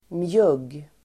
mjugg substantiv, Uttal: [mjug:] Idiom: le i mjugg ("le i smyg") (laugh up one's sleeve ("hide a smile"))